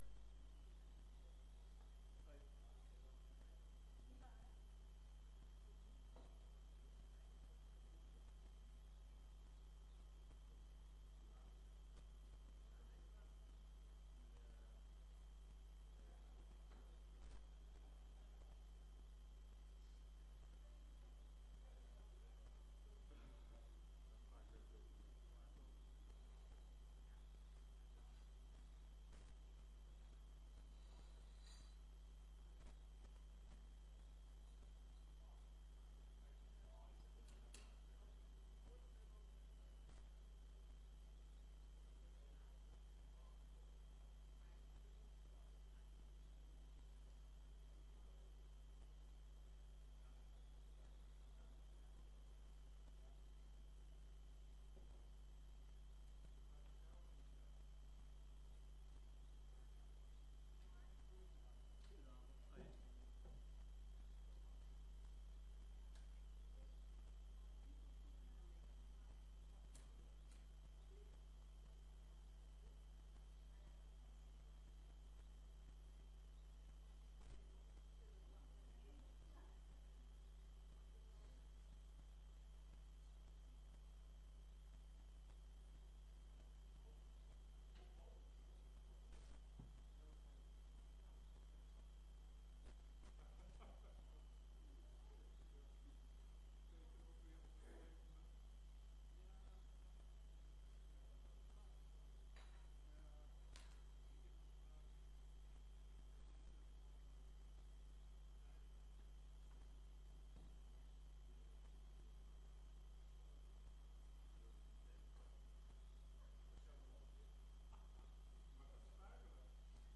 Informatieve raadsvergadering 13 maart 2025 20:00:00, Gemeente Diemen
Locatie: Raadzaal